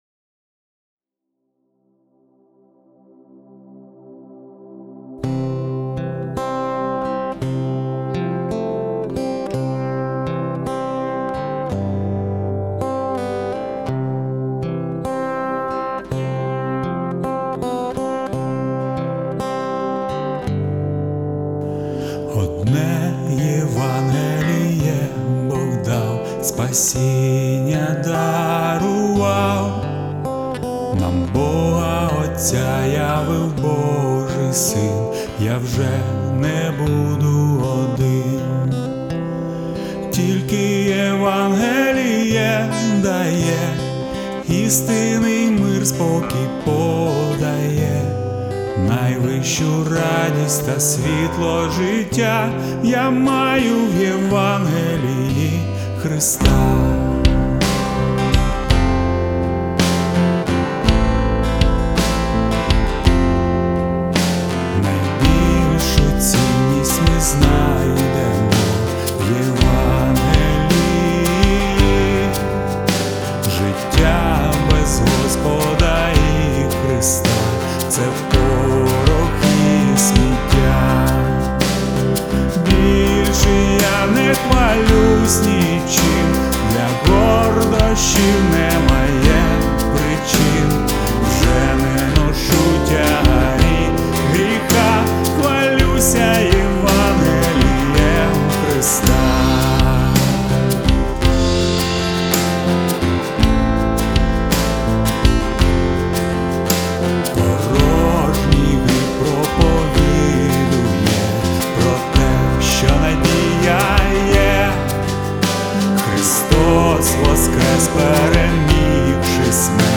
143 просмотра 270 прослушиваний 12 скачиваний BPM: 110